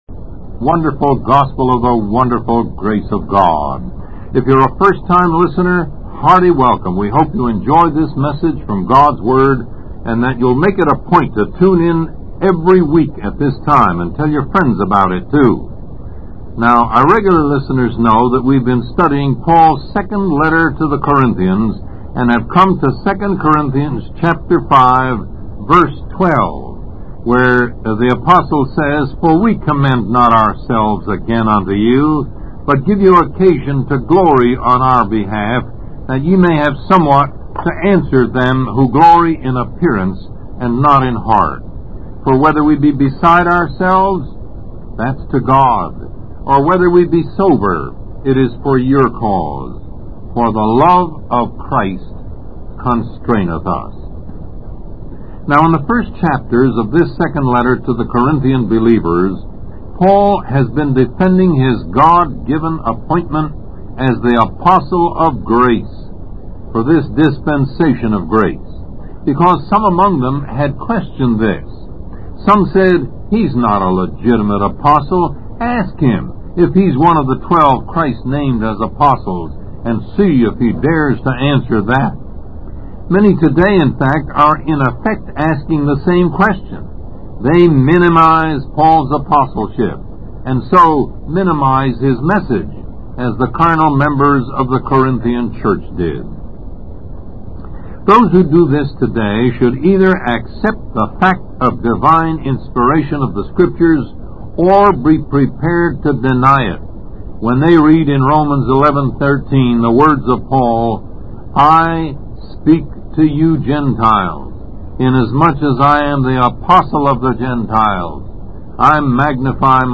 Lesson 25